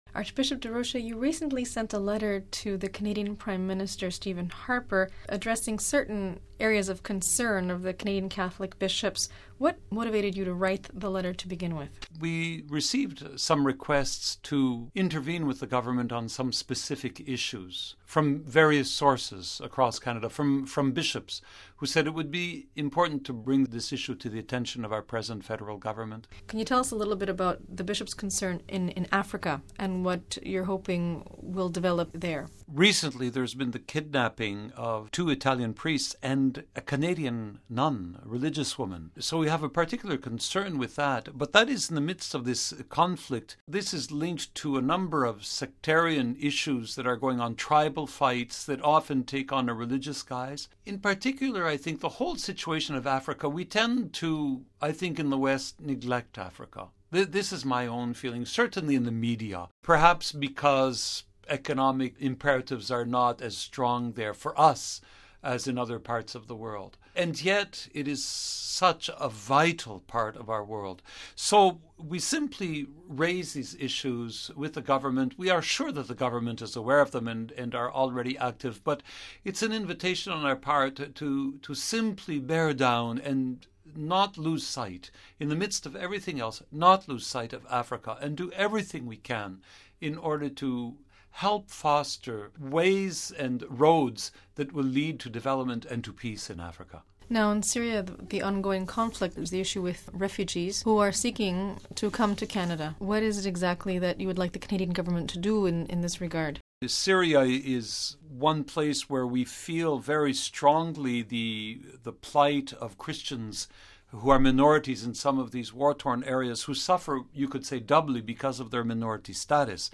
Listen to the full interview with Archbishop Paul-André Durocher : Report and interview